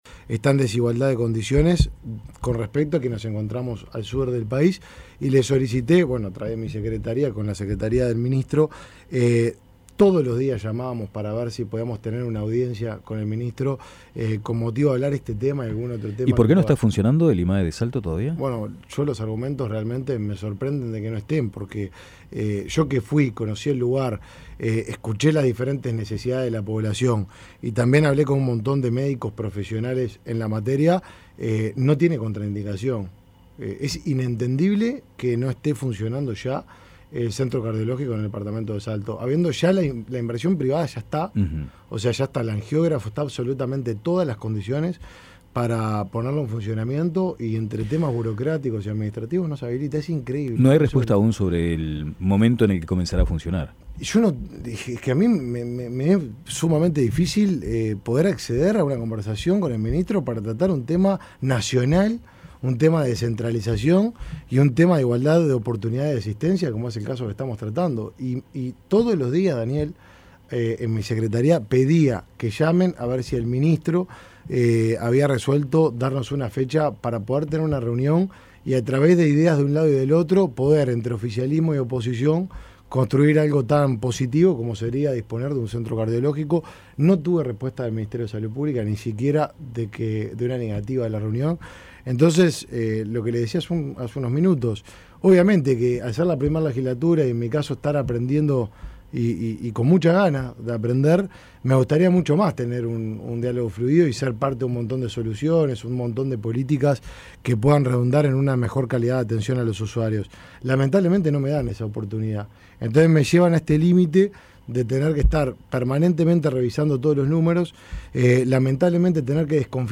Entrevista a Martín Lema